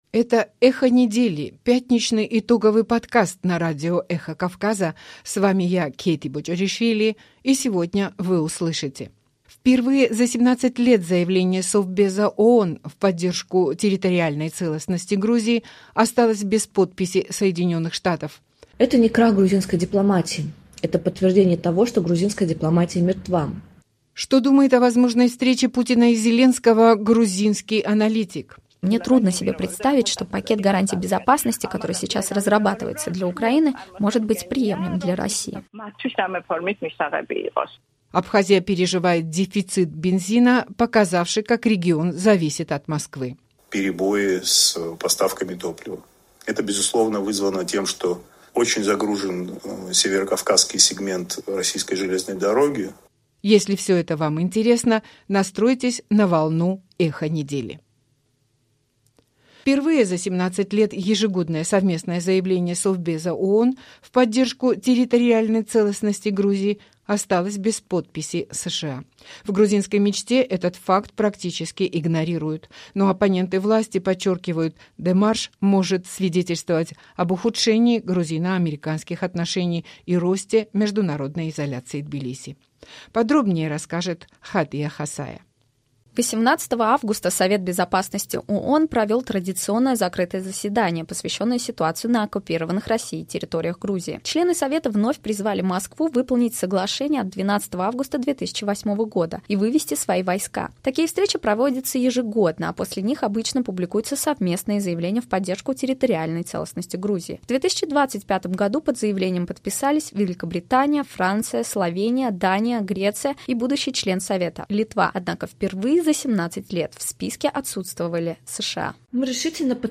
Эхо недели - еженедельный итоговый информационно-аналитический подкаст на Радио "Эхо Кавказа"